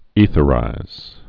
(ēthə-rīz)